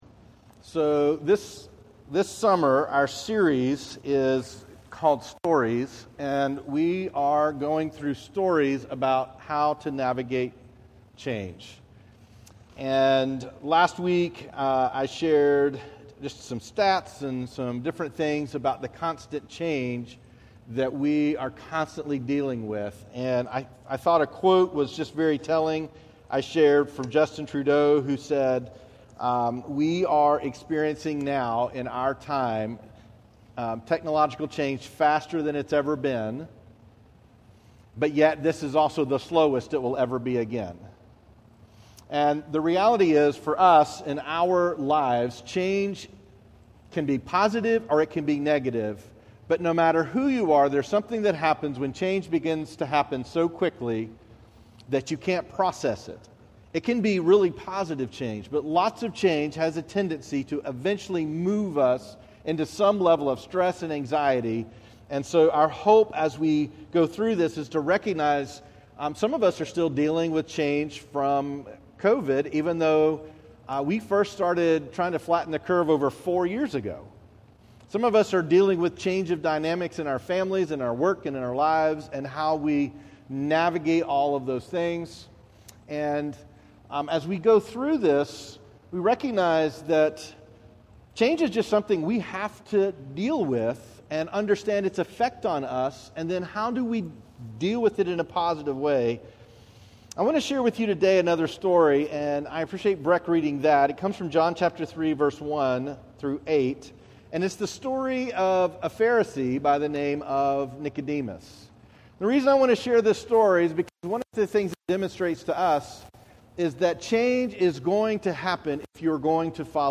A message from the series "Stories."